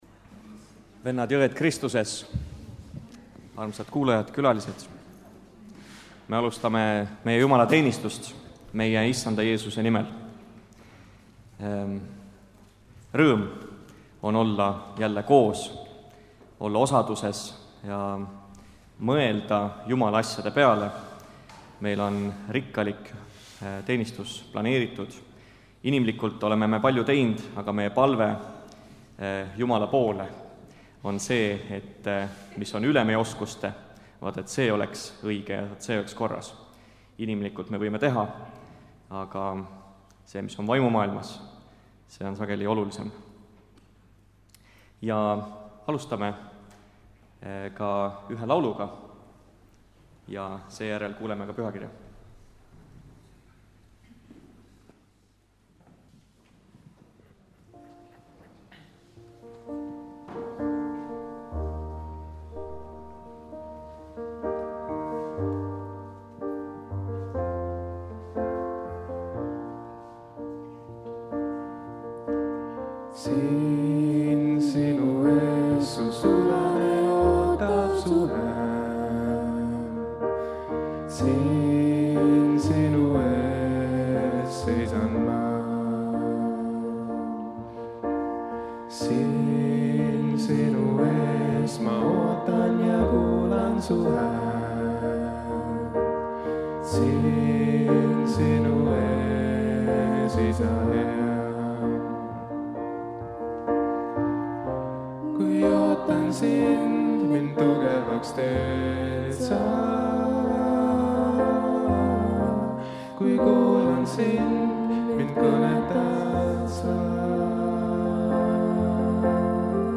Kõik jutlused